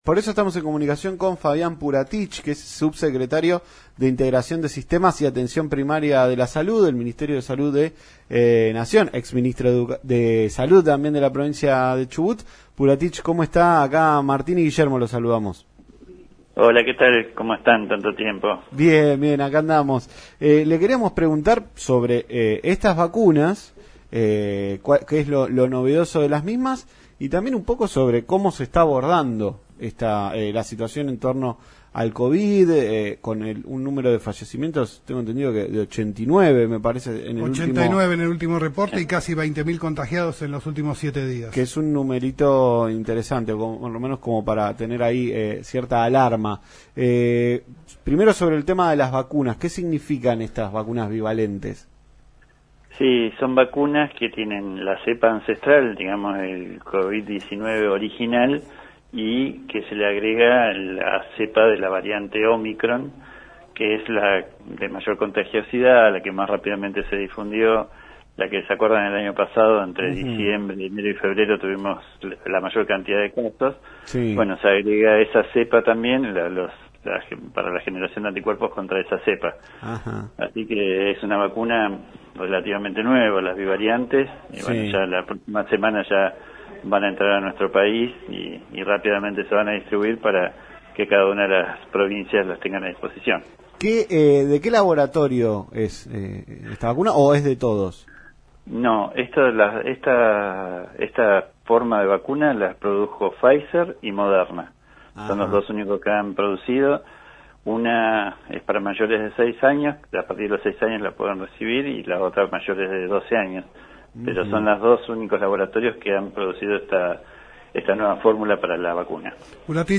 Fabián Puratich, Sub-Secretario de Integración de Sistemas y Atención Primaria de la Salud, dialogó con Tarde Para Miles por LaCienPuntoUno sobre la llegada de vacunas bivalentes contra el coronavirus. Además, se refirió al escenario político en la provincia de Chubut y la decisión de Trelew de adelantar las elecciones para el 16 de abril.